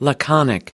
/lǝ kän´ik/adj.کوتاه و مختصر، کم گو، موجز